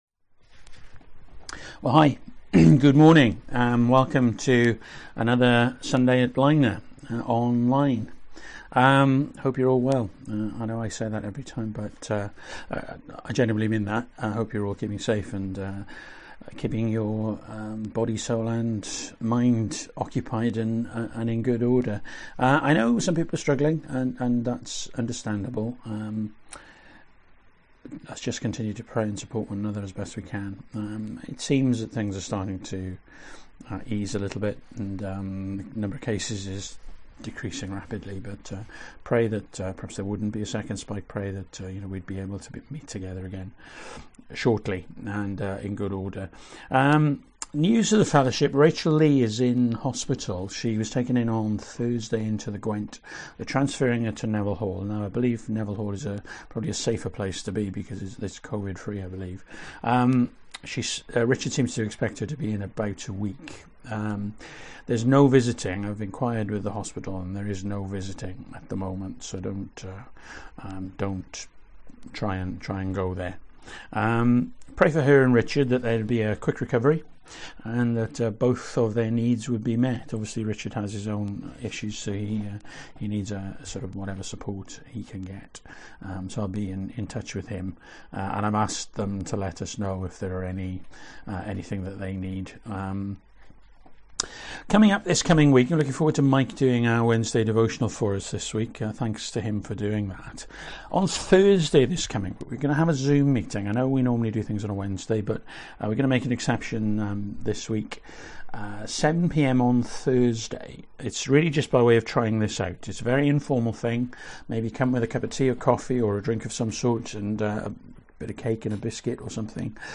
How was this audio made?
1 Tim 3:1-13 Service Type: Morning Bible Text